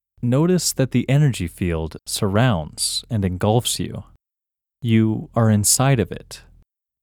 OUT – English Male 4